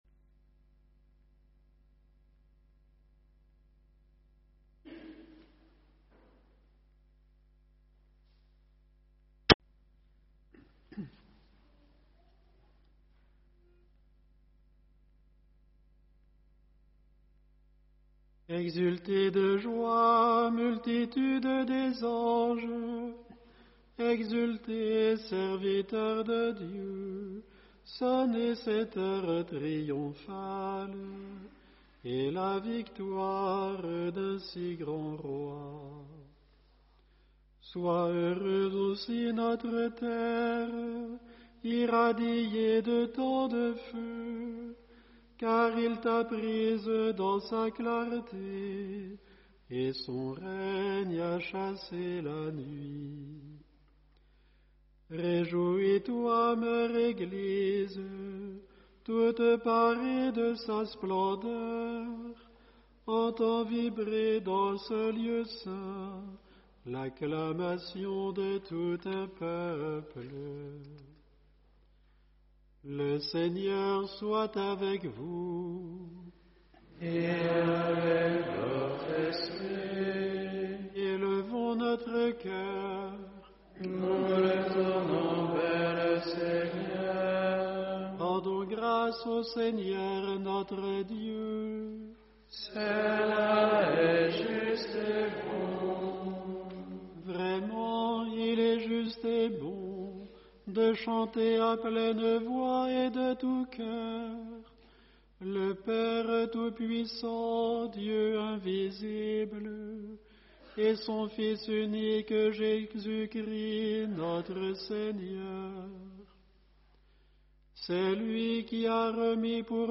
VEILLÉE PASCALE Samedi-saint 19 avril 2025